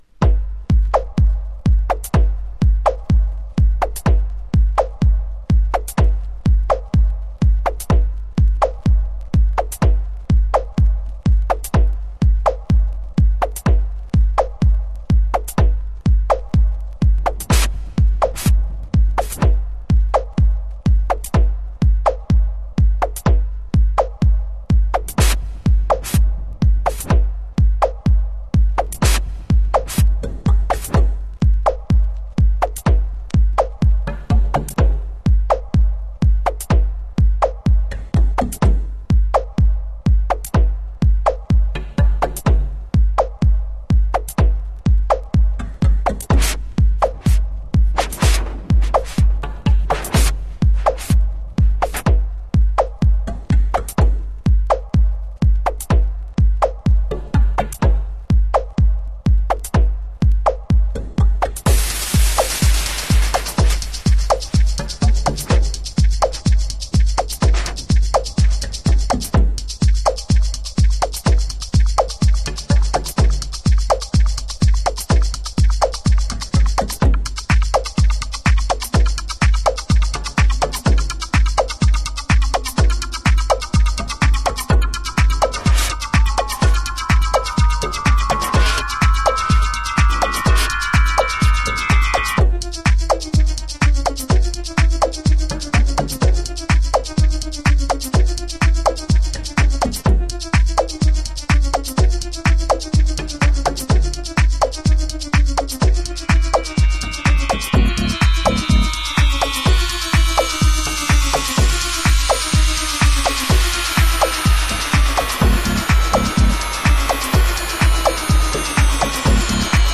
WEST COAST / WICKED HOUSE (2003)